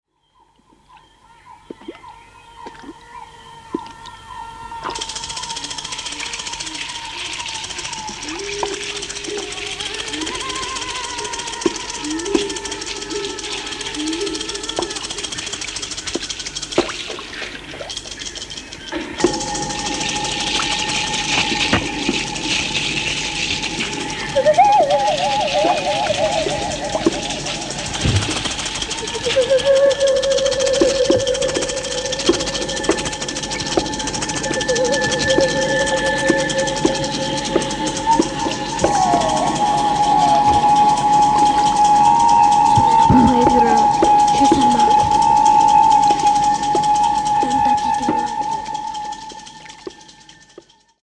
増幅された森の霊気と変調された動物の鳴き声が迫りくる激ドープな音響体験。
サウンドスケープ